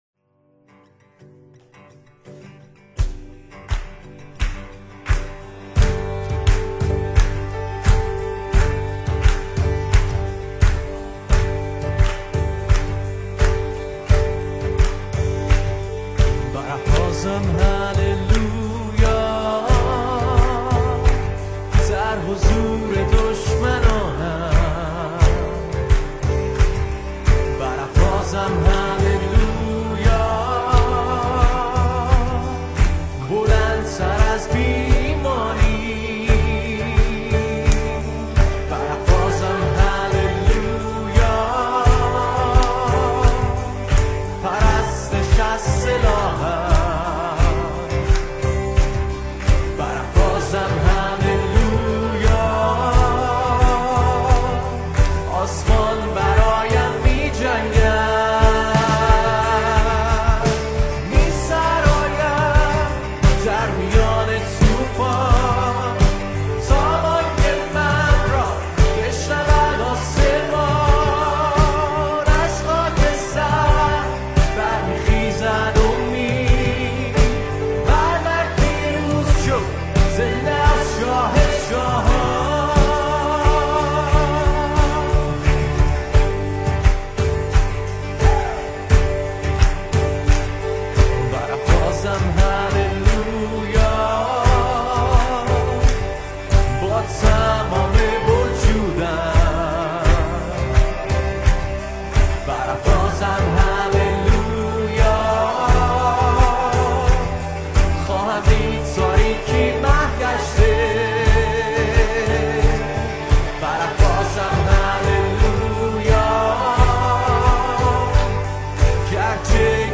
ماژور
Major